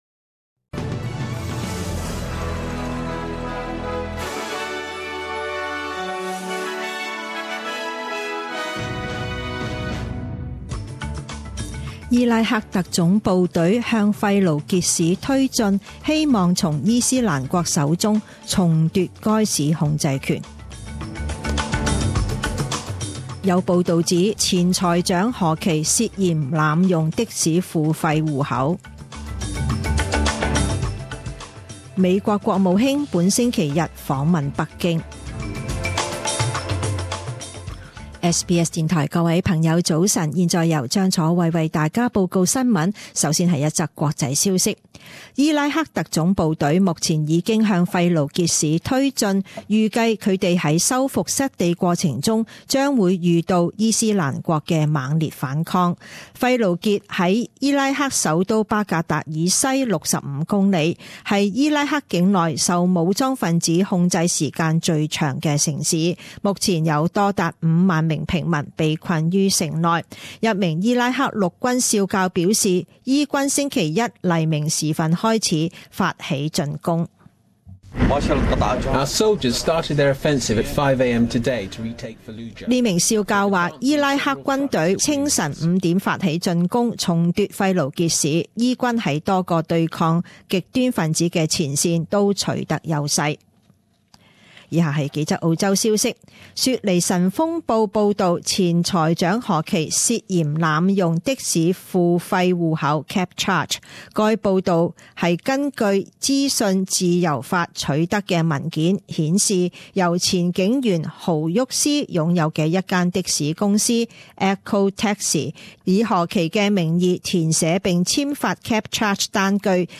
五月三十一日十点钟新闻报导